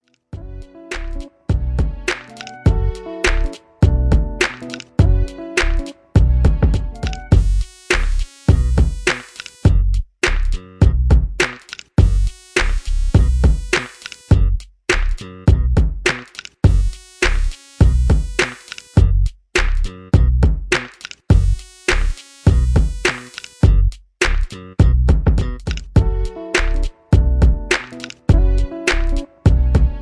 Laid Back Hip Hop Beat